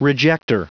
Prononciation du mot rejector en anglais (fichier audio)
Prononciation du mot : rejector